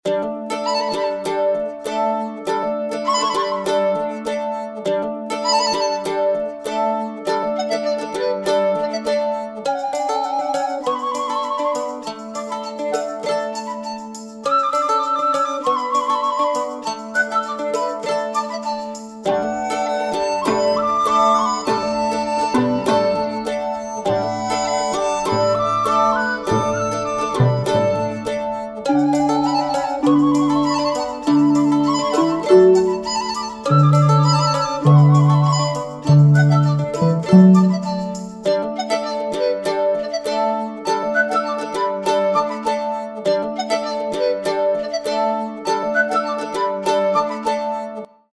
[Resource 4: Music - Mischievous] (